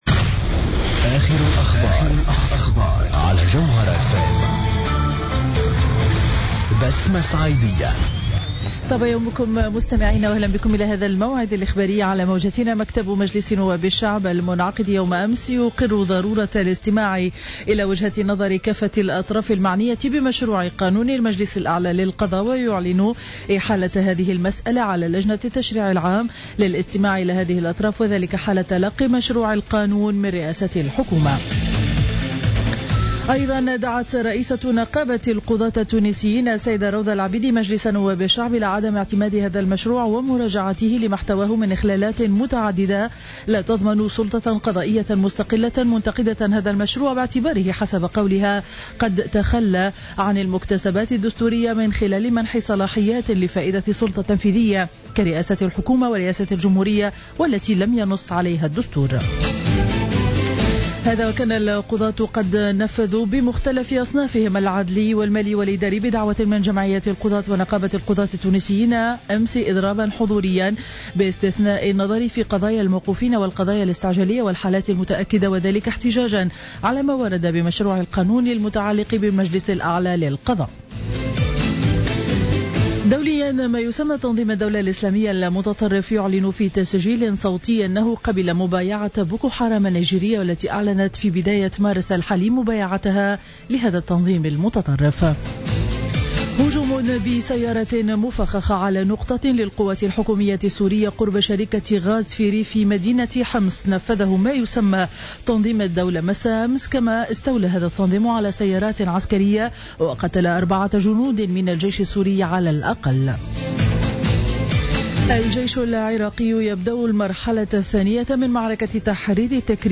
نشرة أخبار السابعة صباحا ليوم الجمعة 13 مارس 2015